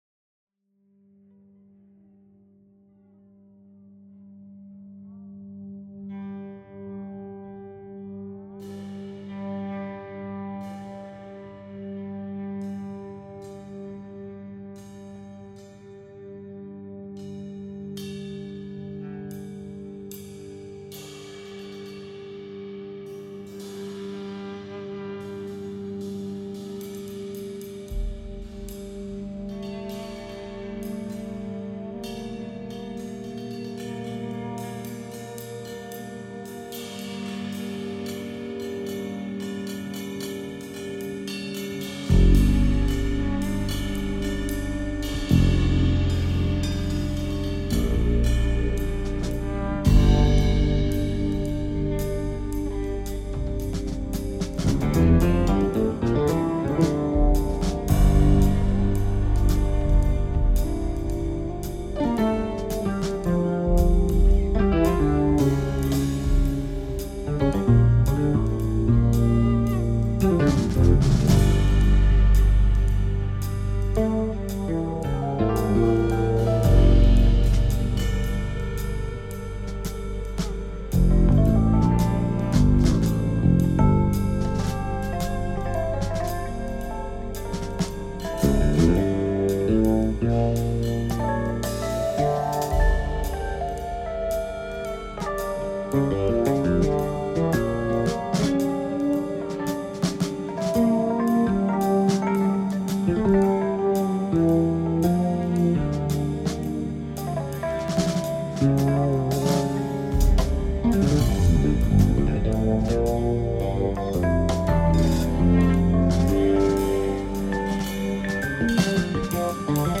Oksana. Ballade pour les femmes Ukrainiennes qui subissent une guerre voulue par un homme.